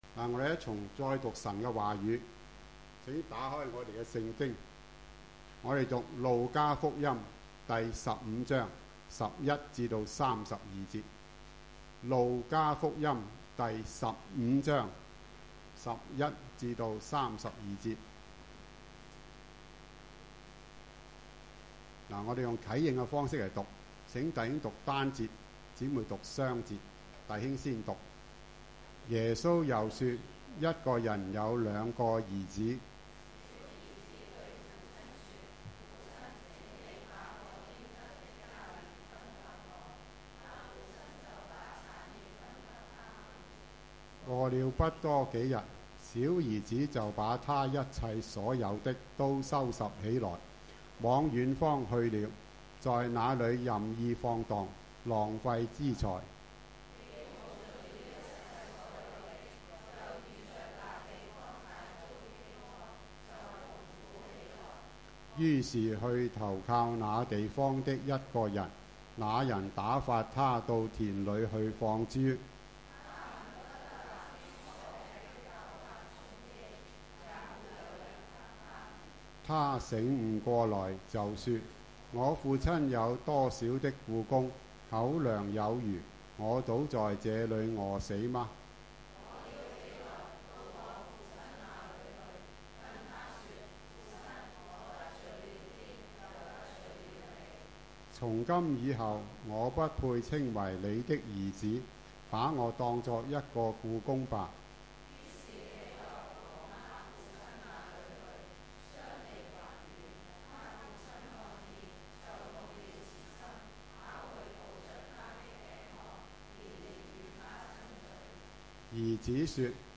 主日崇拜講道 ： 浪子回頭 – 一個歸家的故事